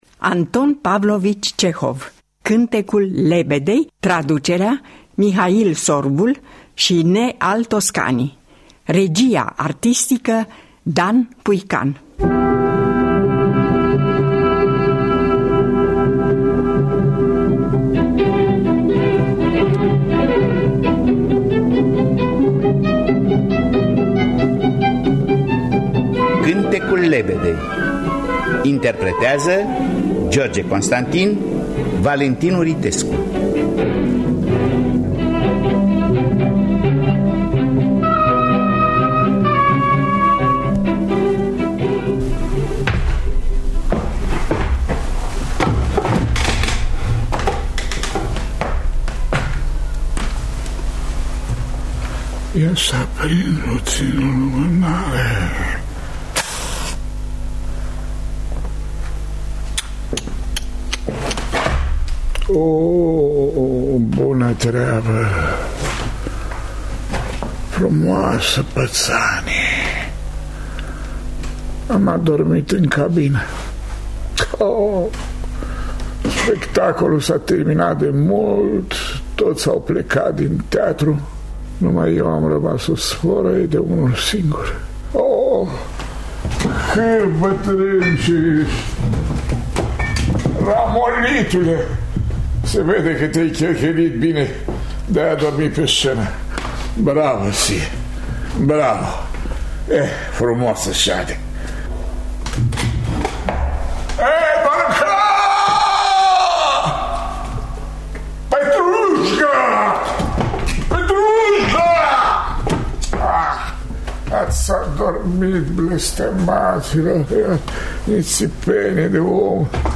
În distribuţie: George Constantin, Valentin Uritescu.